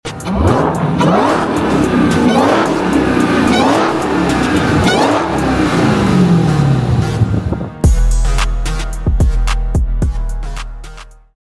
TRX Roar In Open Field Sound Effects Free Download